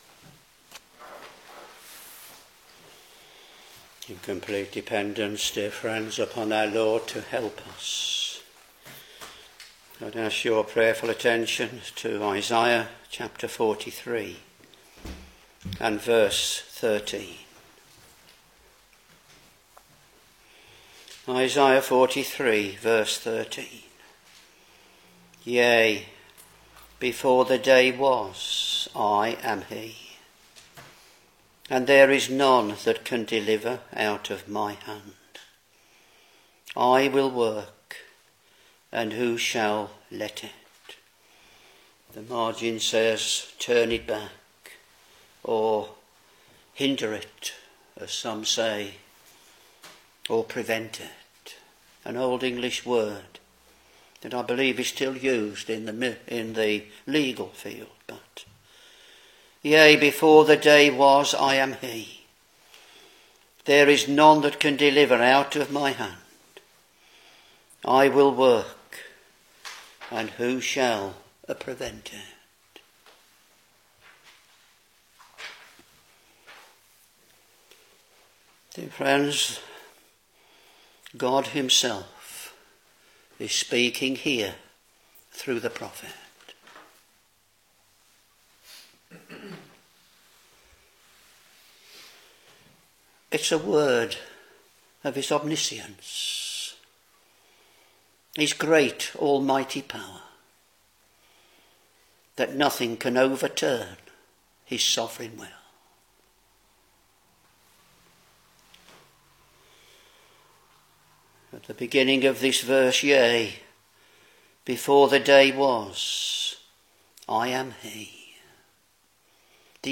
Sermons Isaiah Ch.43 v.13